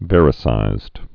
(vârĭ-sīzd, văr-)